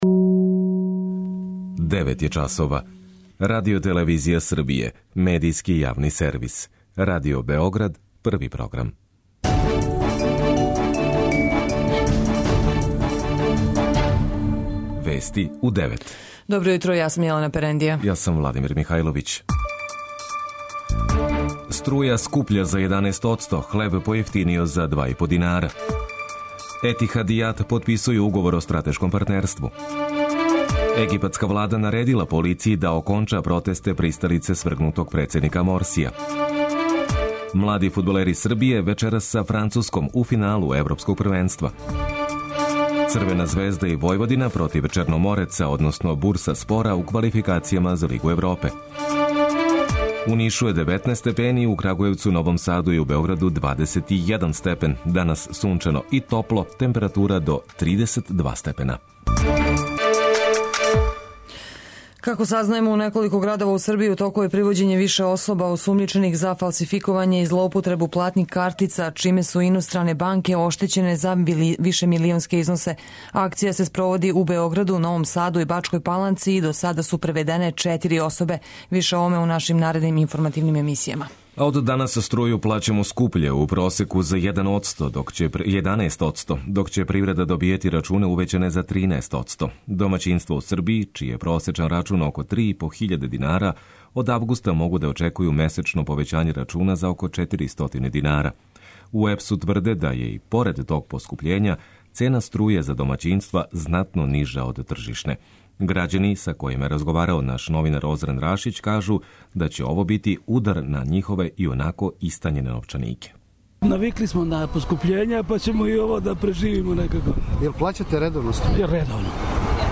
преузми : 9.81 MB Вести у 9 Autor: разни аутори Преглед најважнијиx информација из земље из света.